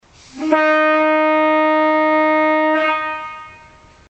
The fundamental of the horn that produced the samples below is 310 Hz, or roughly D#.